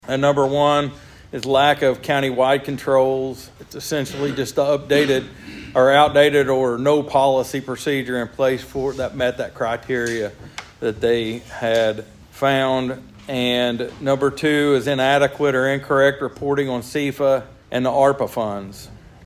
District two commissioner Steve Talburt said there were